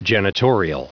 Prononciation du mot janitorial en anglais (fichier audio)
janitorial.wav